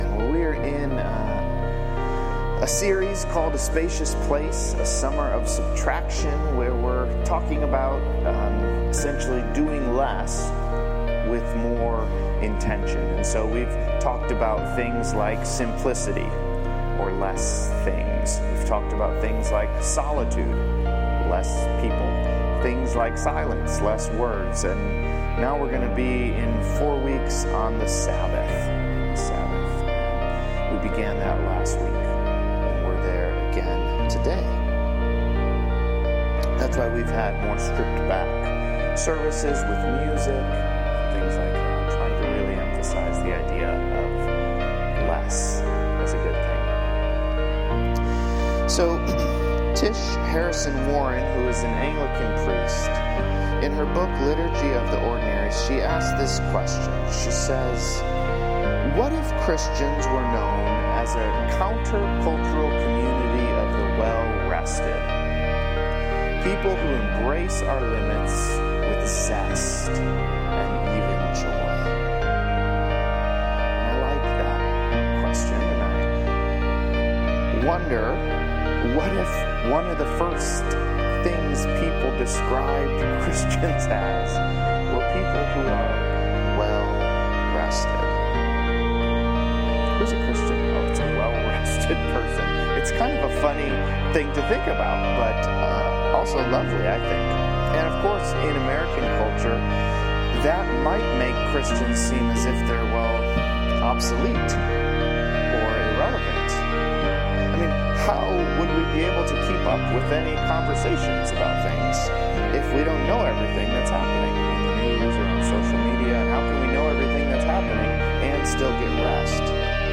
Download We apologize for the background music in the first four minutes of the sermon recording